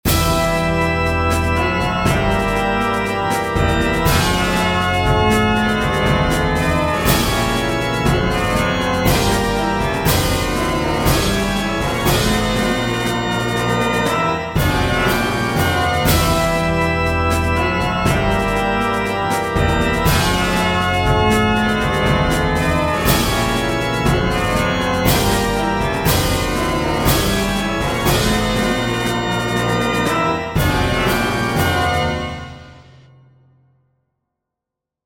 Short 120bpm loop in 8edo